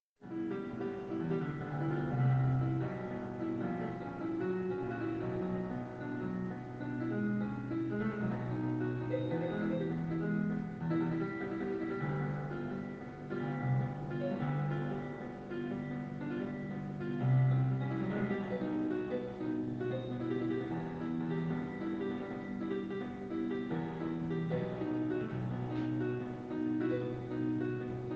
Also, the quality is pretty poor, because I recorded it with my smartphone, to show it some friends.
Moonlight Sonata inspired: